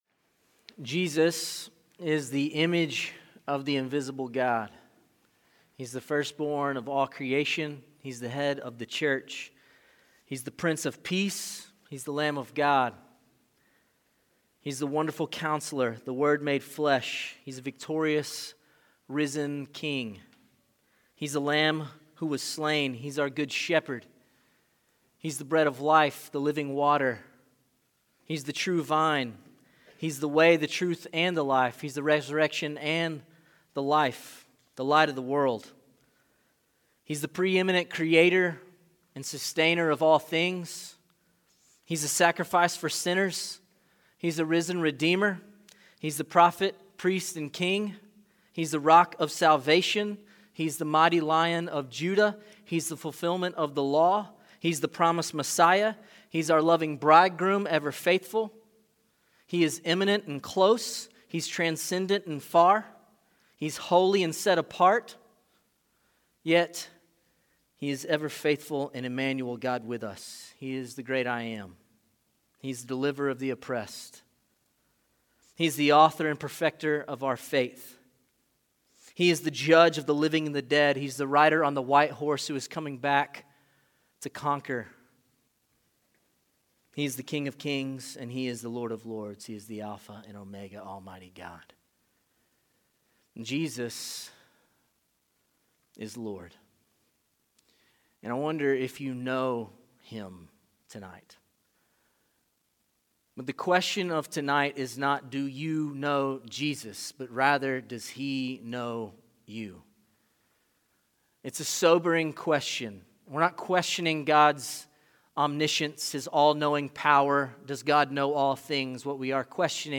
City View Church - Sermons "Does Jesus Know You?"